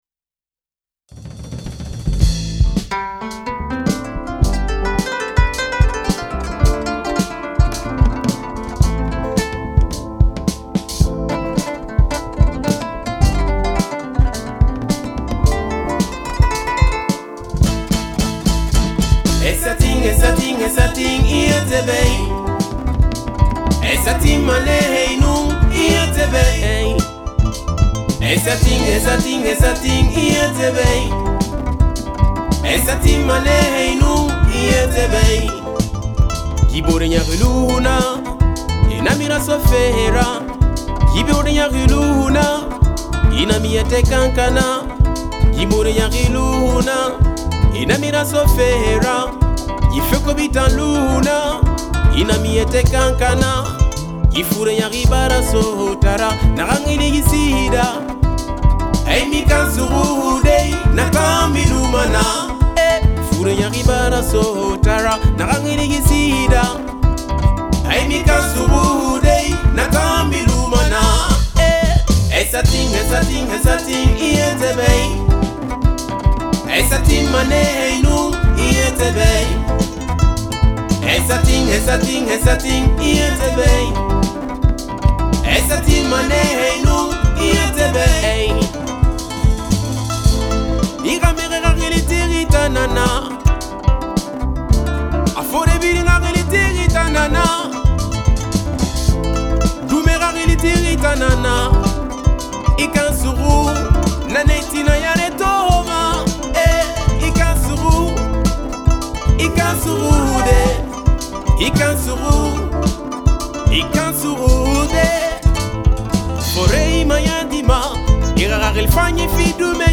Groupe de musique tradimoderne manding de Guinée composé de:
chanté en soussou  – dialecte de Guinée